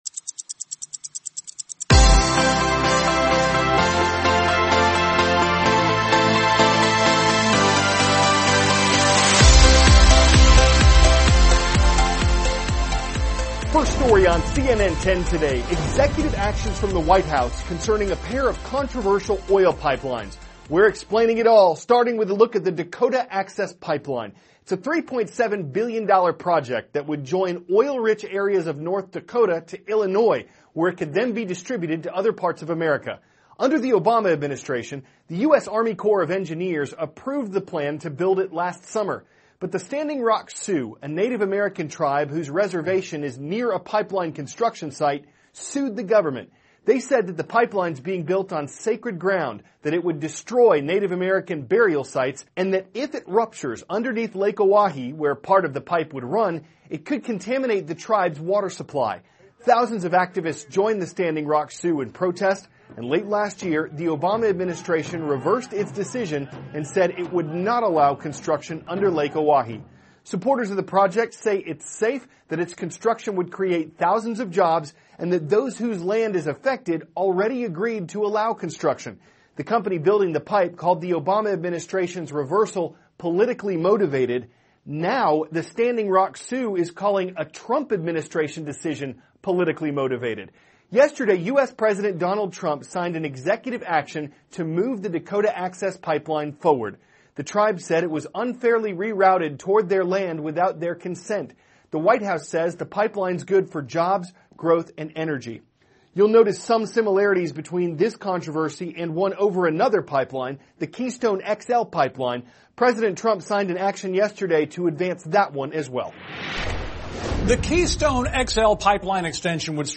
*** CARL AZUZ, cnn 10 ANCHOR: First story on cnn 10 today: executive actions from the White House concerning a pair of controversial oil pipelines.